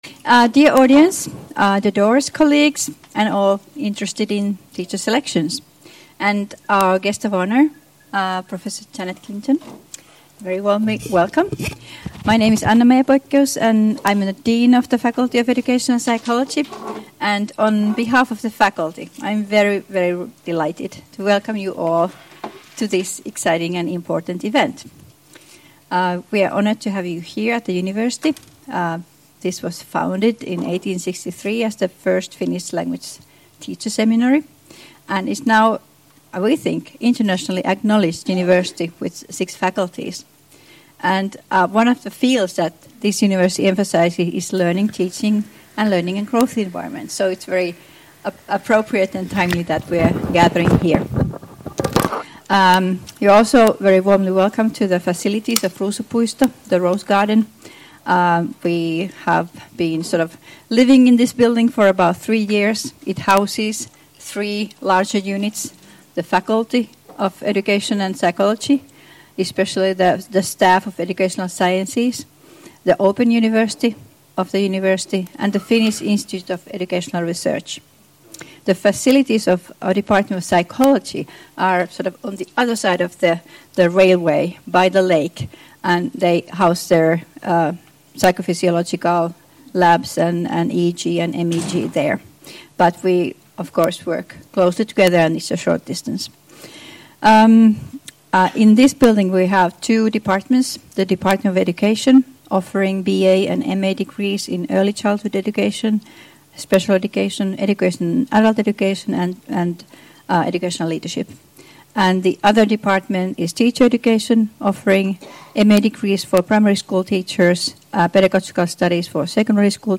Seminaarissa käsitellään opettajankoulutuksen opiskelijavalintojen kehittämistä.